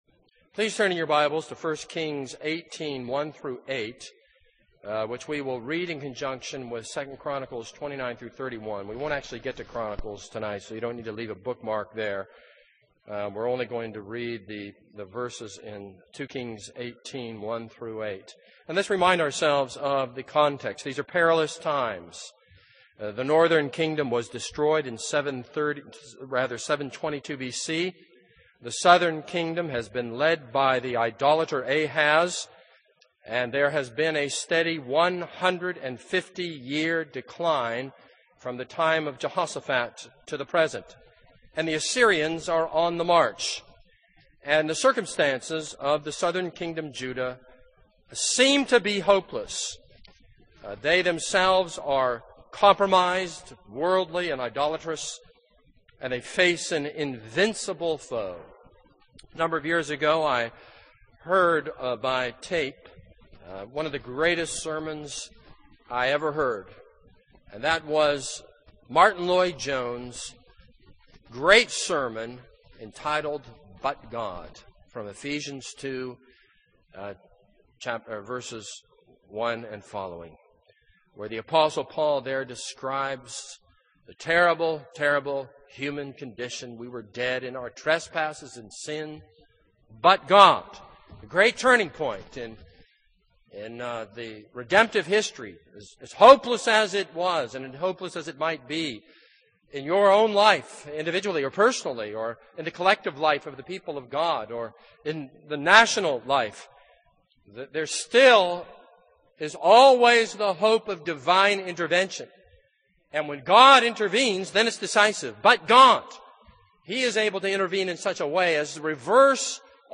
This is a sermon on 2 Kings 18:1-8.